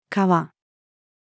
河-female.mp3